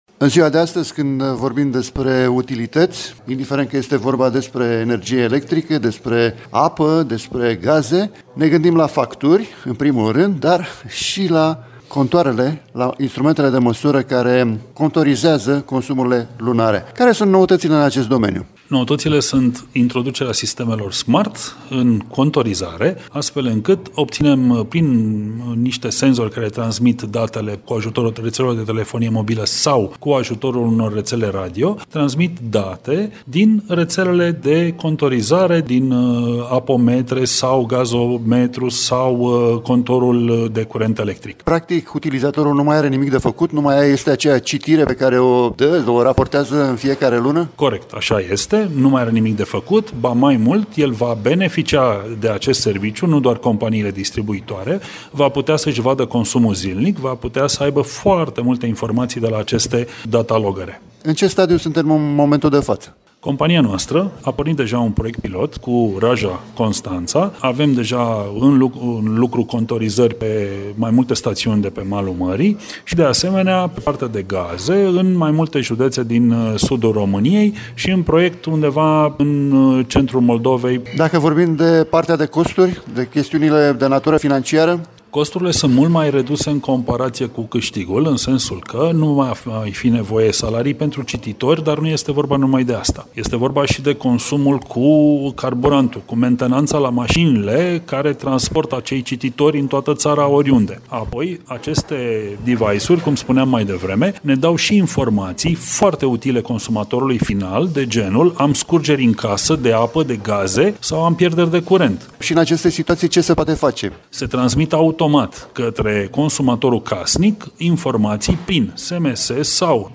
în dialog cu